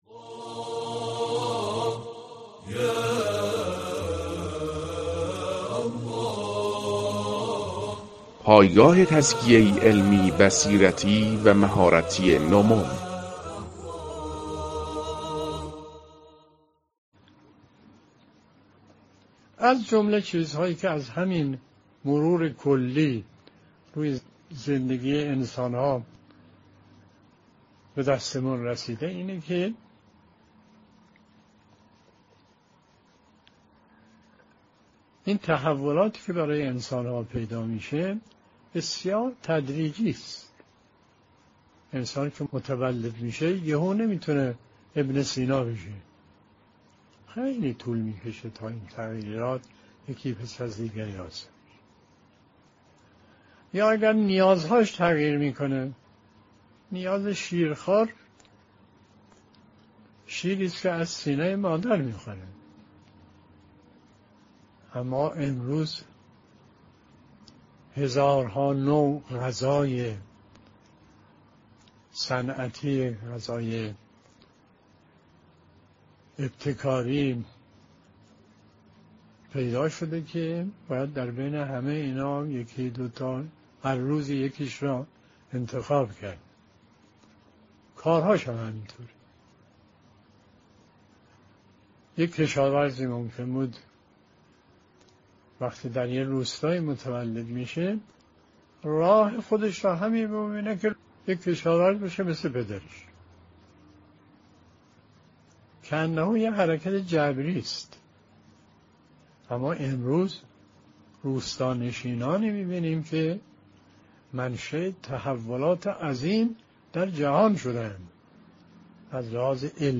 🔰 بیانات آیت الله مصباح یزدی درباره ضرورت تحول در عالم